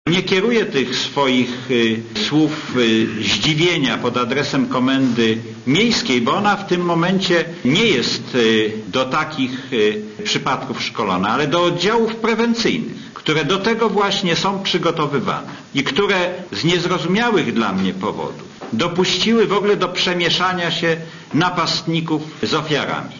Mówi Jerzy Kropiwnicki (80 KB)